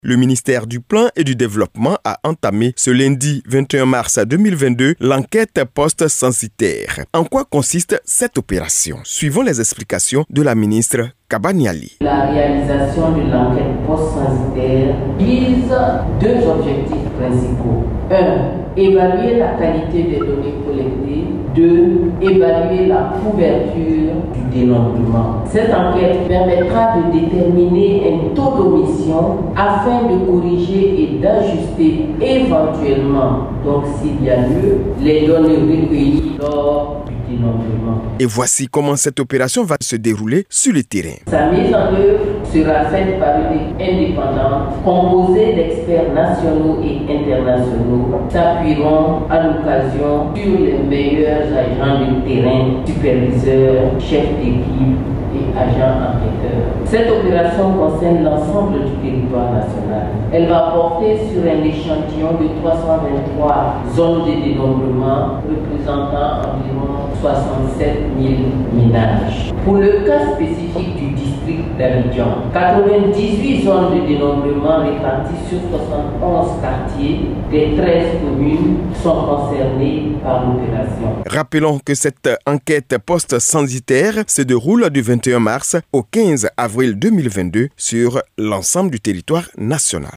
compte de rendu